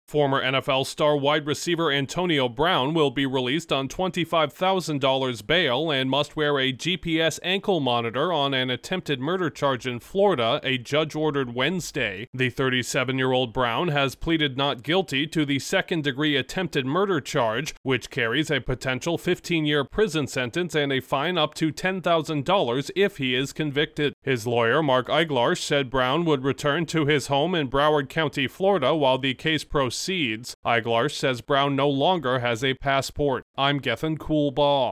A retired former NFL star facing an attempted murder charge has been released on bail. Correspondent